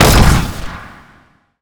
pgs/Assets/Audio/Sci-Fi Weapons/sci-fi_weapon_rifle_large_shot_02.wav at master
sci-fi_weapon_rifle_large_shot_02.wav